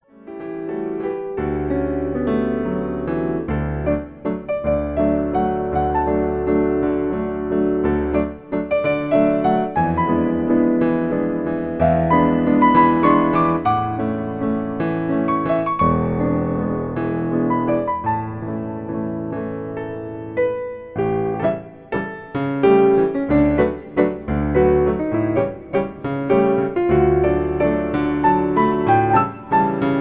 lovely melodies